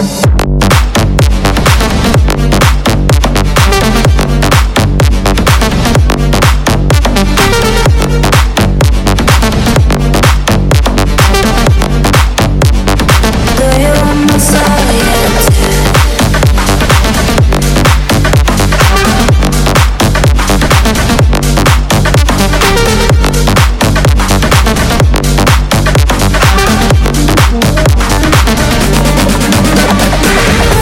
электроника